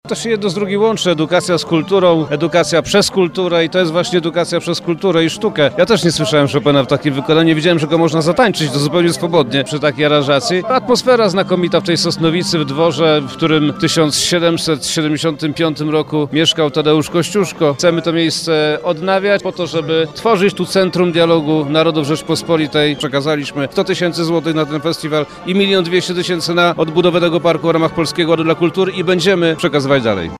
Klasyczne i jazzowe aranżacje utworów Fryderyka Chopina wybrzmiały w zabytkowym Parku przy Dworku Kościuszki w Sosnowicy.